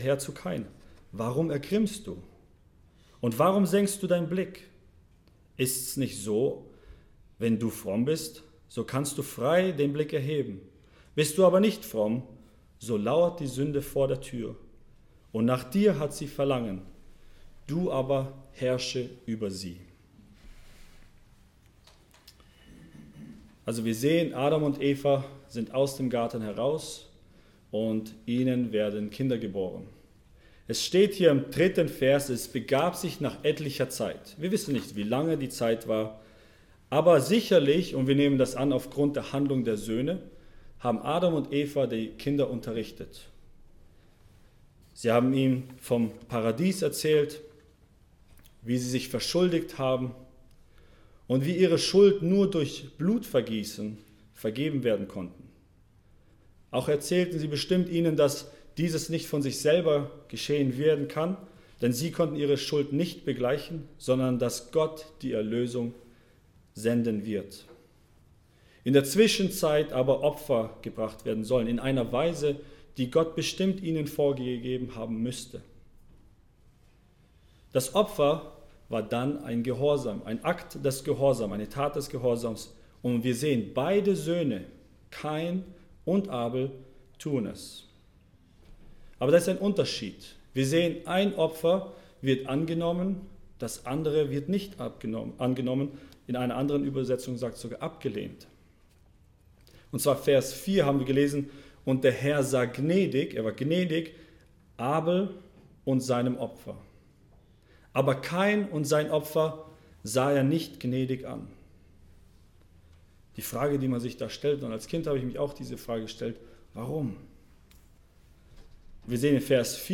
Erntedankpredigt